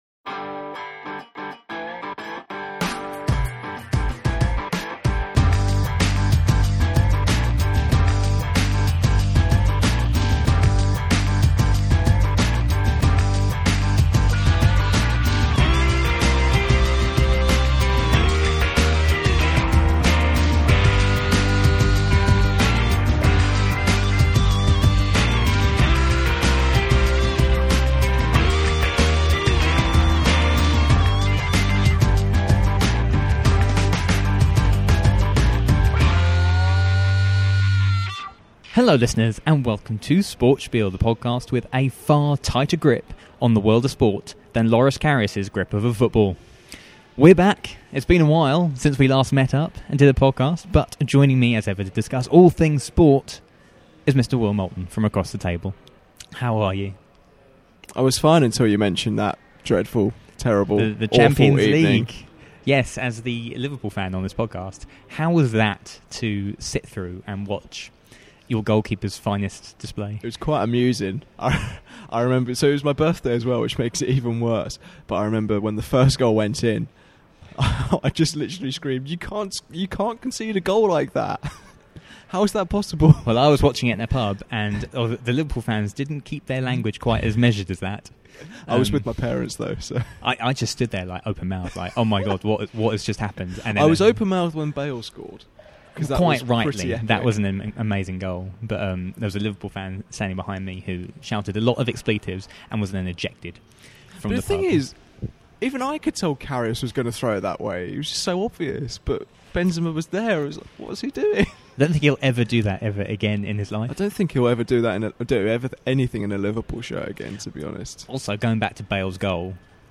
Our feature interview segment